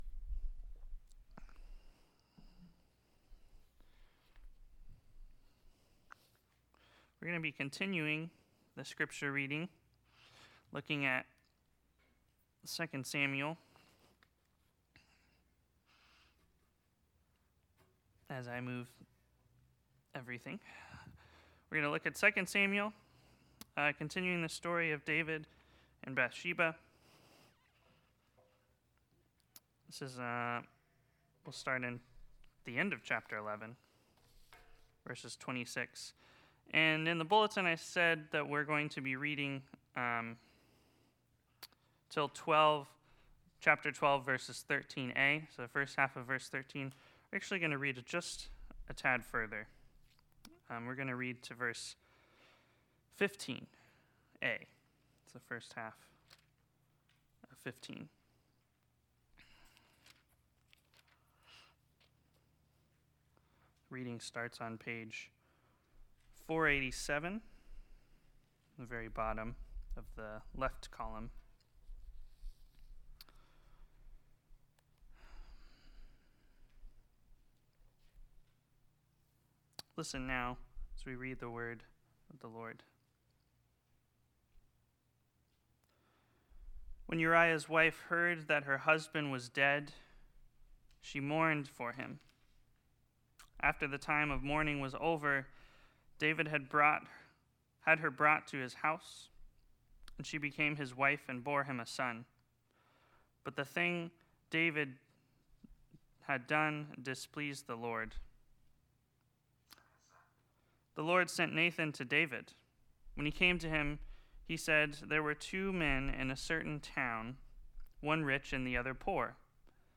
Sermon Recordings | Trinity United Methodist Church of Lakeland